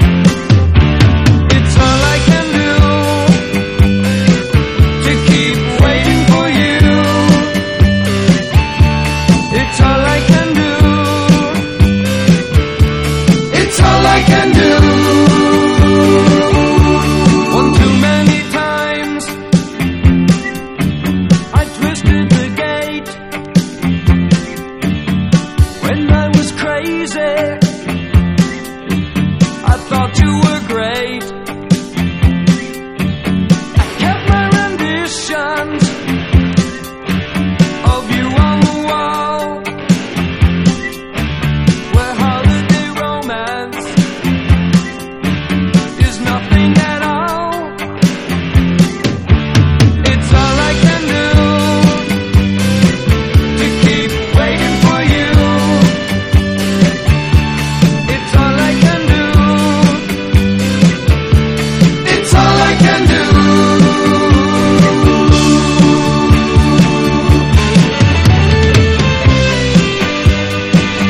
ROCK / 80'S/NEW WAVE. / NEW WAVE / POWER POP / 70'S